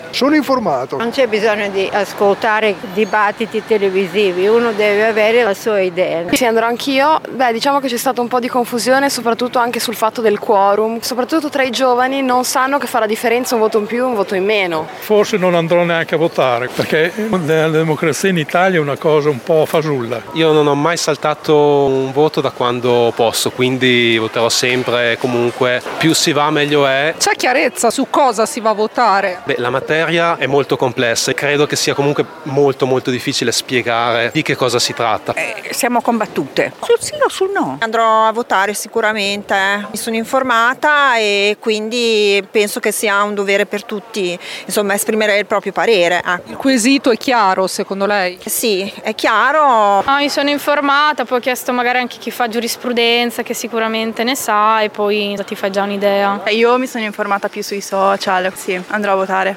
Quanto sono informati i cittadini? Qui sotto le interviste